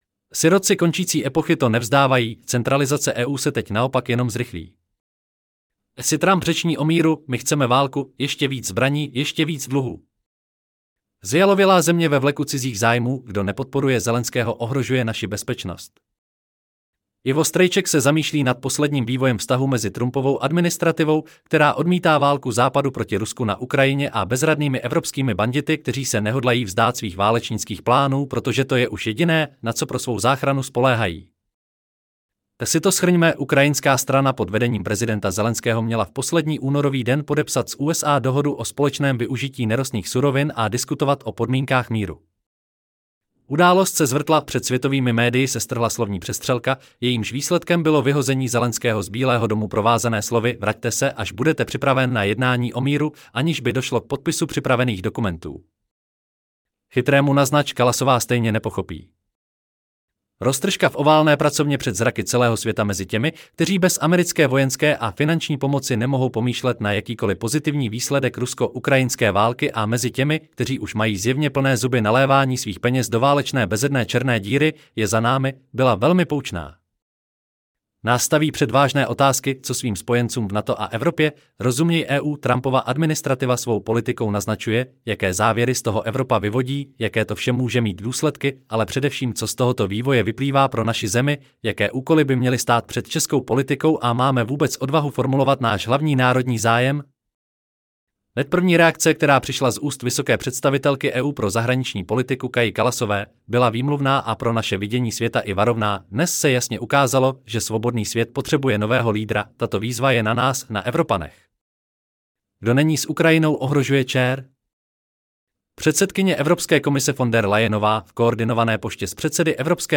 Celý článek si můžete poslechnout v audioverzi zde: Sirotci-koncici-epochy-to-nevzdavaji_Centralizace-EU-se-ted-naopak-jenom-zrychli.-At-si-Trump 5.3.2025 Sirotci končící epochy to nevzdávají: Centralizace EU se teď naopak jenom zrychlí.